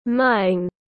Mine /maɪn/